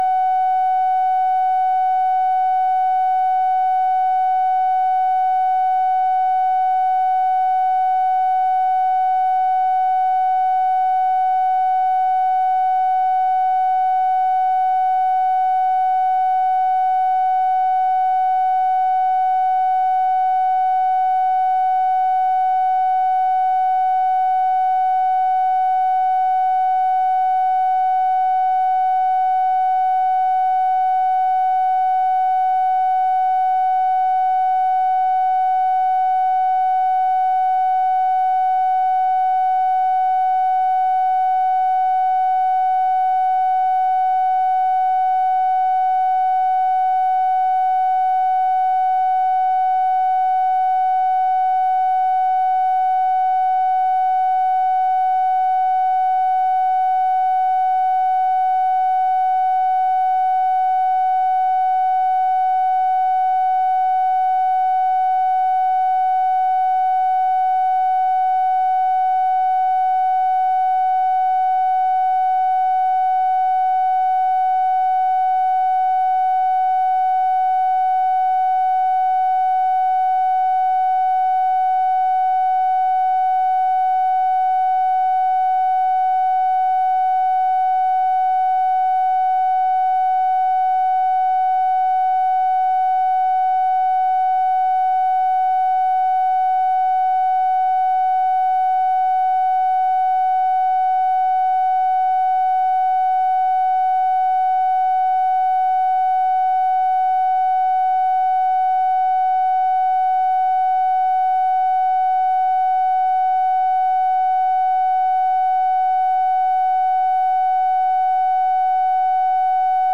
Conversation with DEAN RUSK, August 9, 1964
Secret White House Tapes